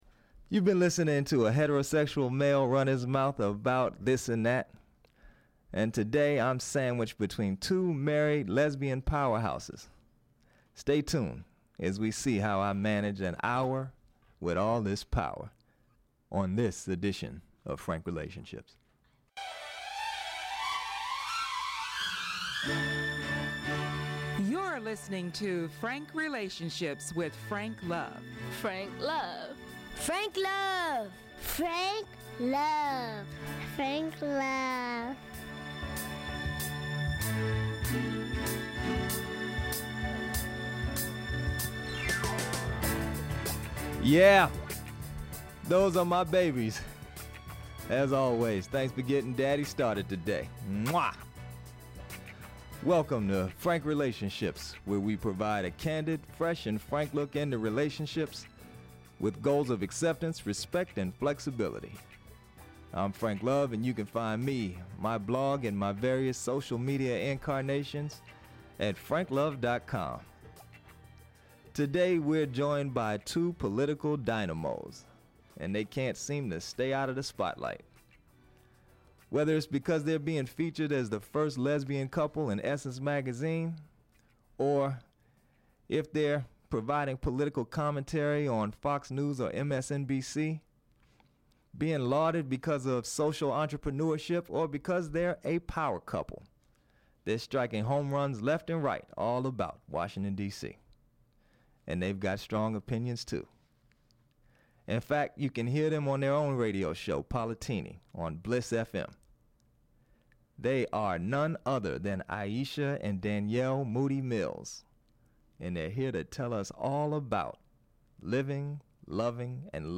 Podcast Episode: Generally speaking, you listen to a heterosexual male run his mouth about this and that. Today, I am sandwiched in between 2 married lesbian powerhouses.